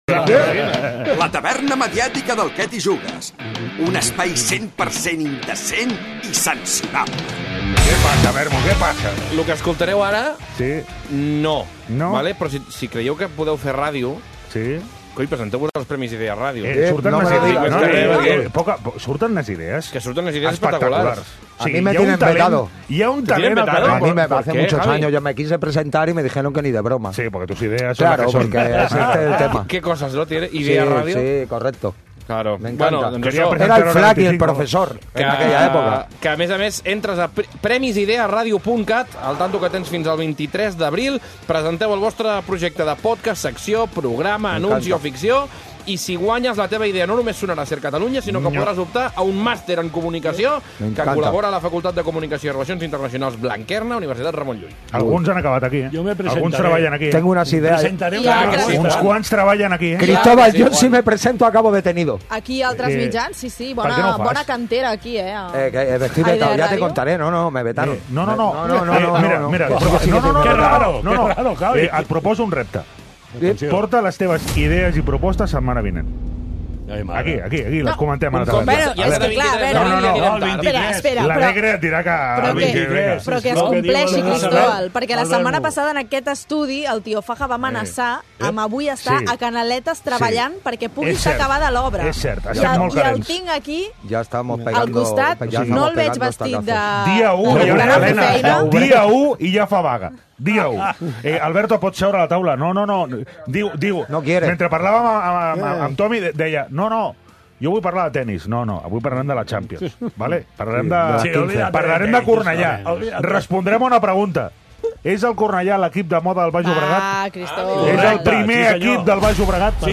Escolta la tertúlia més irreverent de la ràdio